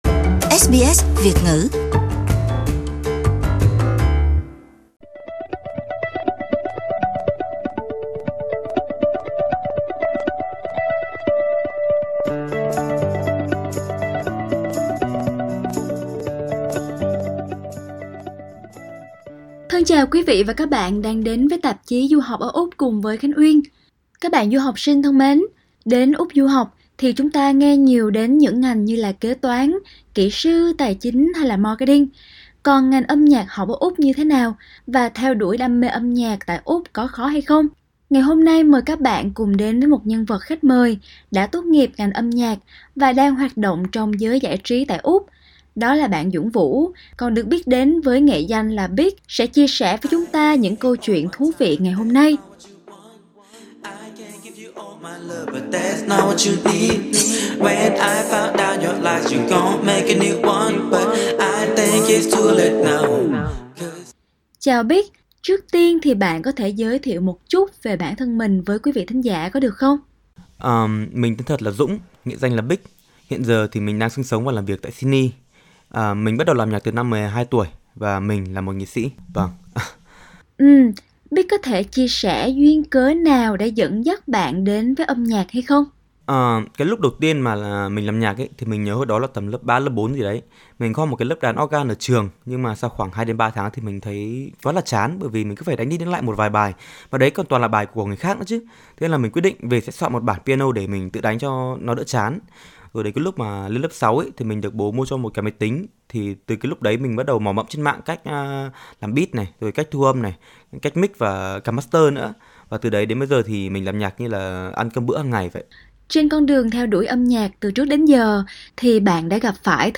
Còn ngành Âm nhạc học ở Úc thì như thế nào? Và theo đuổi đam mê âm nhạc ở Úc có khó không? Trò chuyện